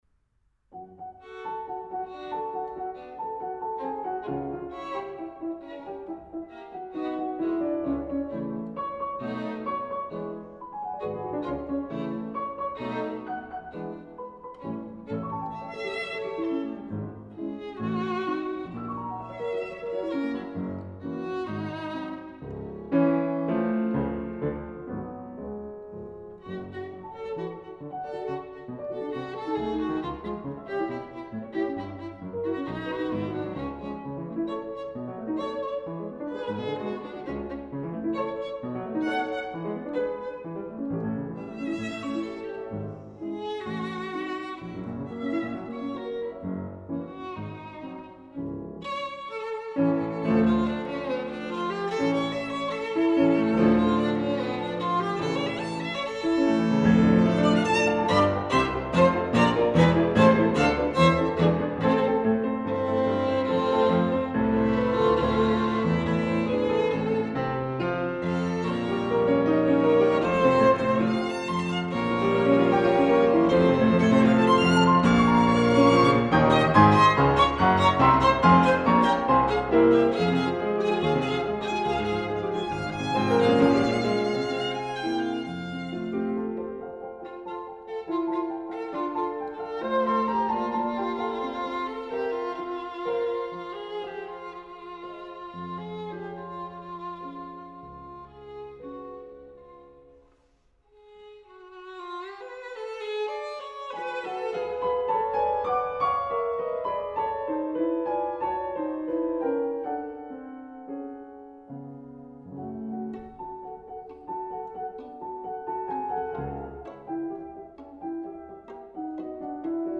Sonaten für Klavier und Violine
Violine